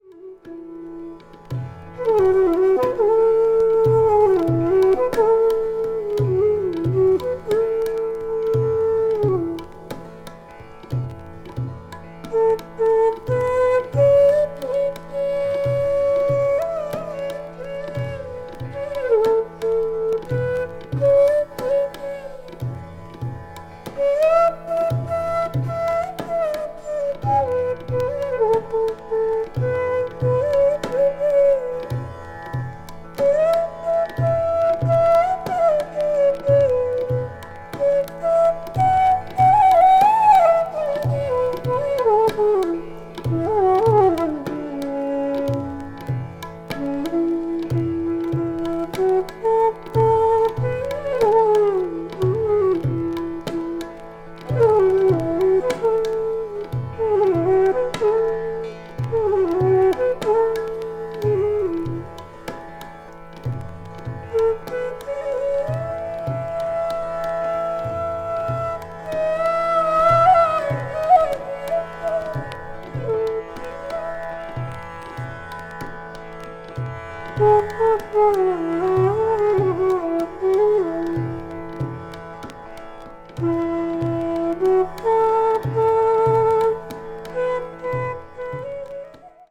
media : EX-/EX-(some light noises.)
tabla
tambura